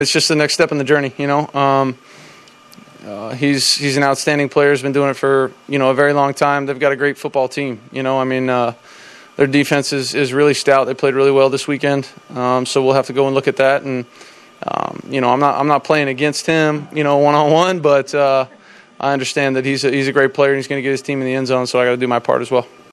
Stafford paid respect to Tom Brady, his next competition, in a post-game interview.